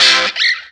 Track 02 - Guitar Stab OS 04.wav